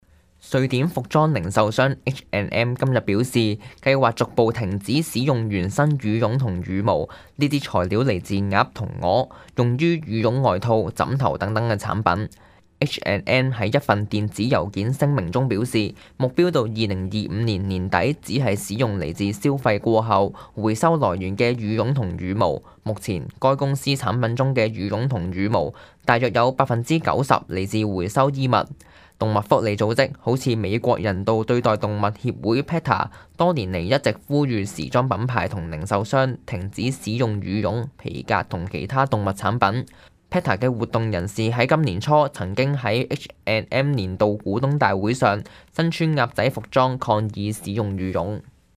news_clip_20813.mp3